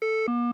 defib_failed.ogg